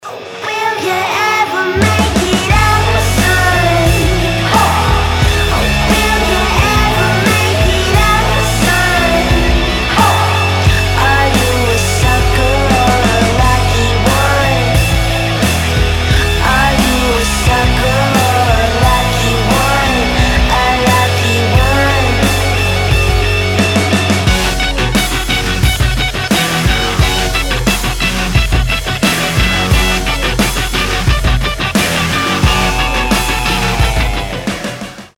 • Качество: 320, Stereo
электрогитара
Alternative Rock
красивый женский голос